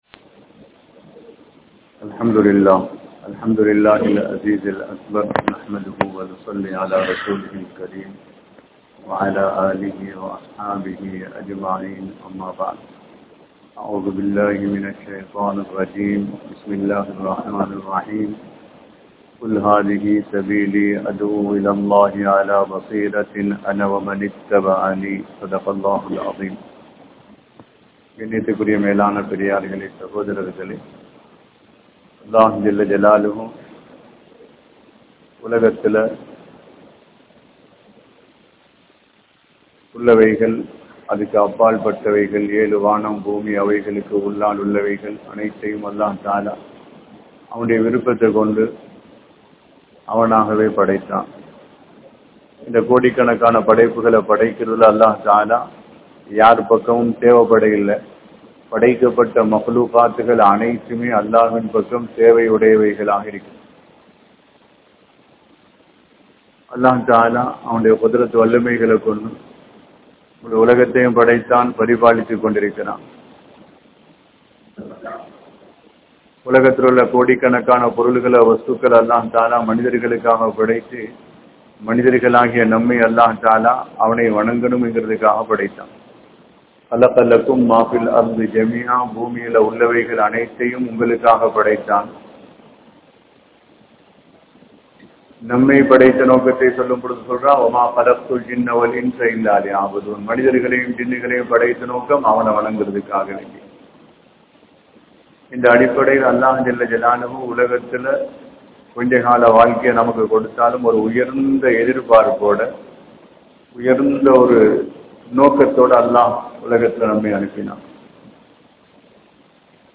Eemaanukkaana Ulaippu (ஈமானுக்கான உழைப்பு) | Audio Bayans | All Ceylon Muslim Youth Community | Addalaichenai